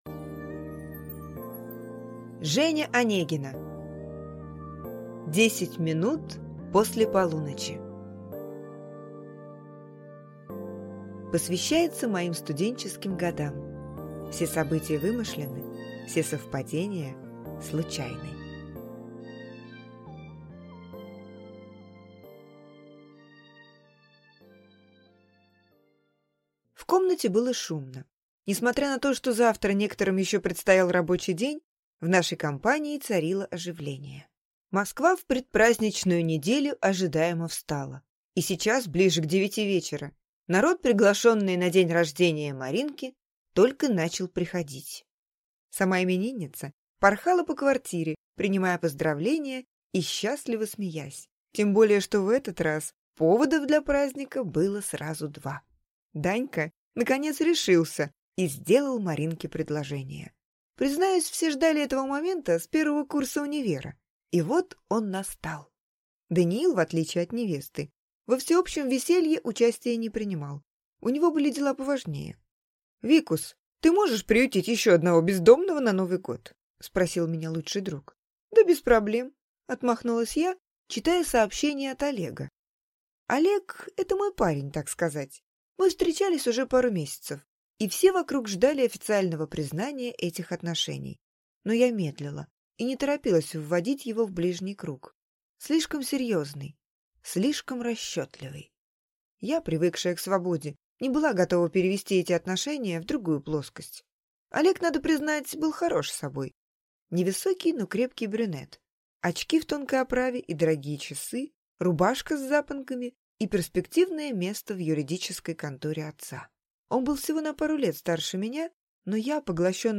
Аудиокнига Десять минут после полуночи | Библиотека аудиокниг